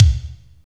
29.03 KICK.wav